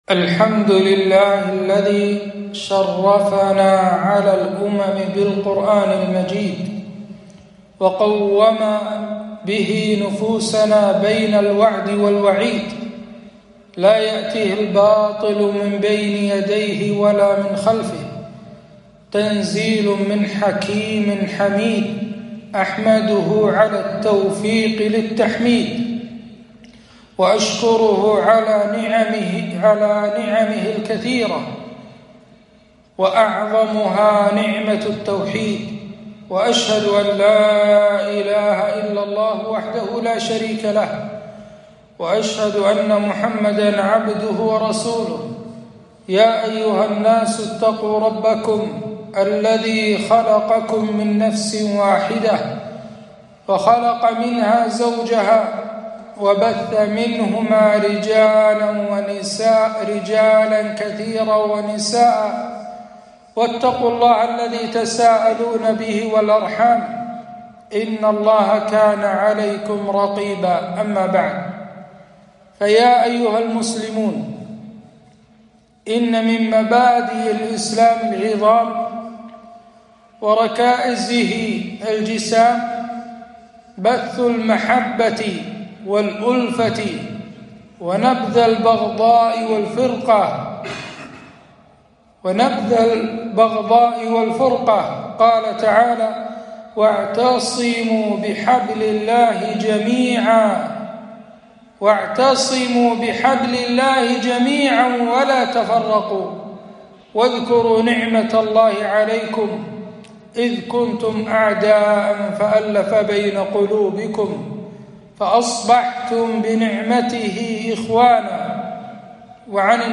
خطبة - صلة الرحم وأثرها على الفرد والمجتمع